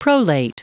Prononciation du mot prolate en anglais (fichier audio)
Prononciation du mot : prolate